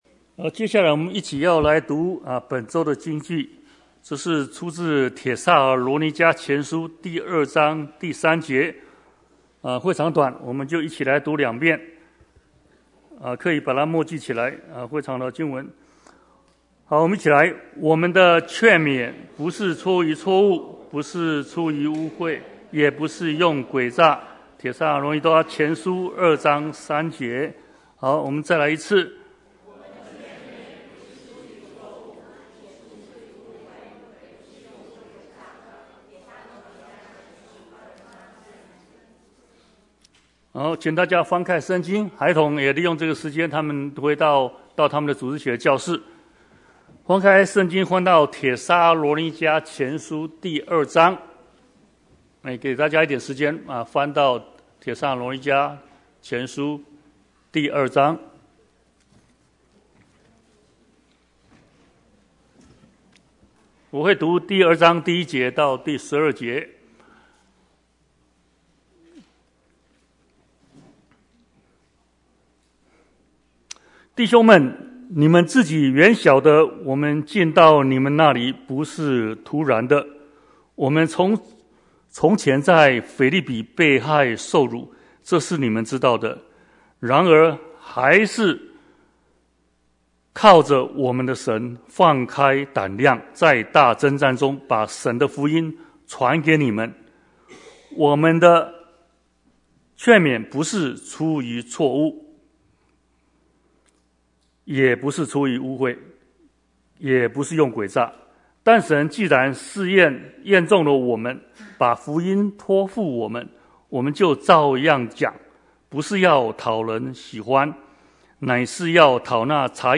Bible Text: 帖前 2:1-20 | Preacher